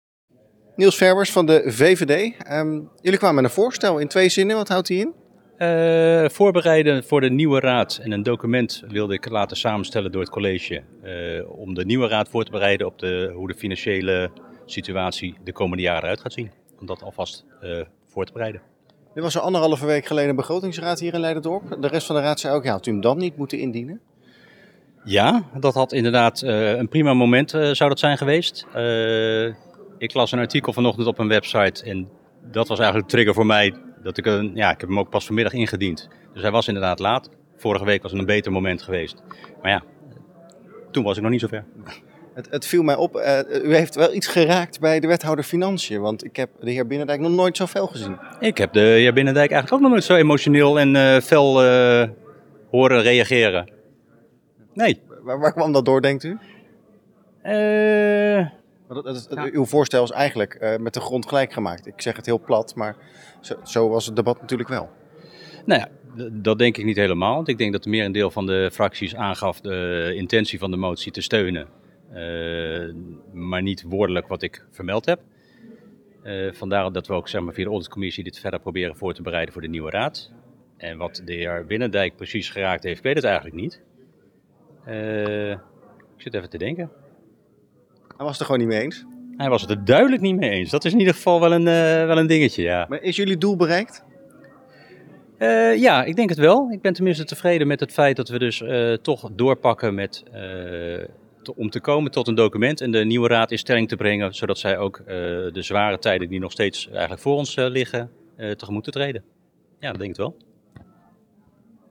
Niels Verwers van de VVD na afloop van de raadsvergadering: